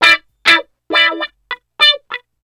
66 GUITAR -L.wav